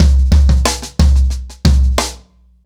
Wireless-90BPM.43.wav